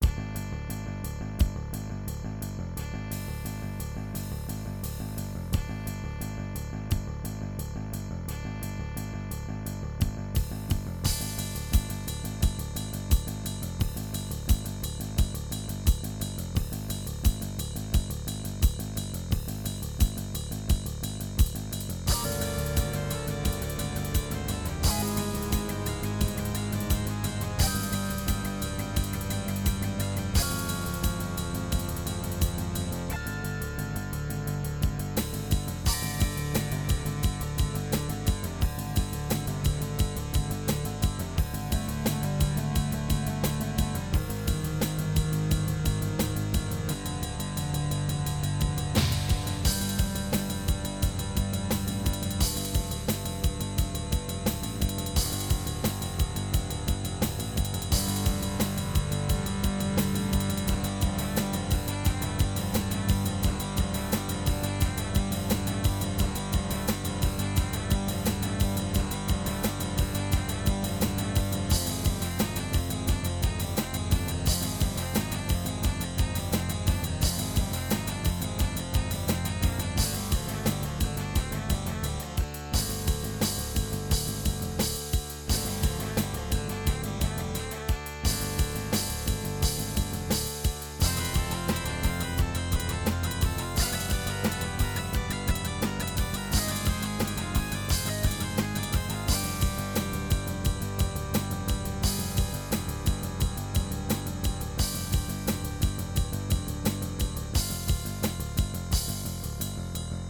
Music: GM midi
Ensoniq Sounscape S-2000